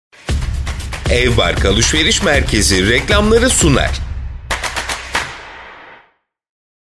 VoiceBros International Media - Professional Online Voice-over Portal
Male